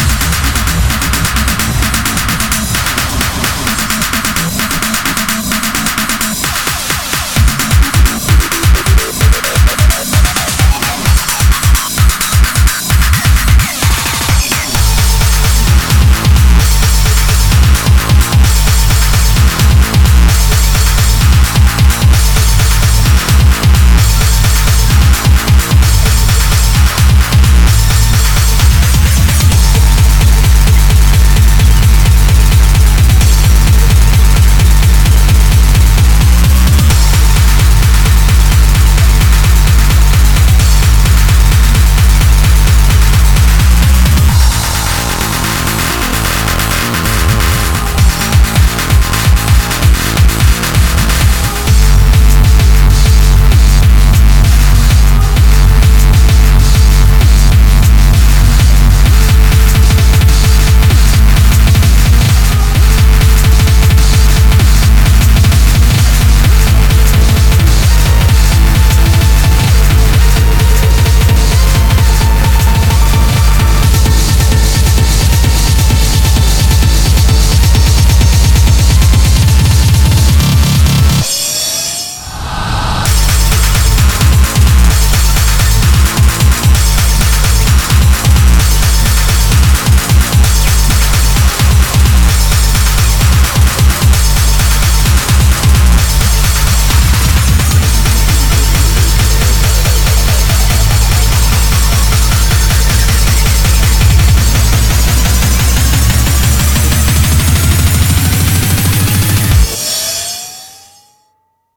BPM130-520